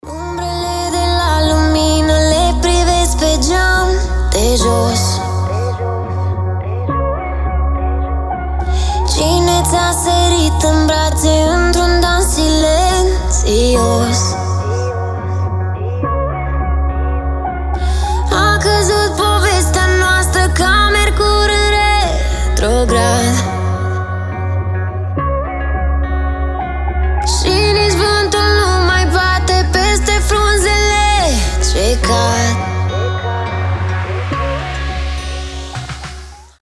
• Качество: 320, Stereo
поп
мелодичные
спокойные
медленные
романтичные
красивый женский вокал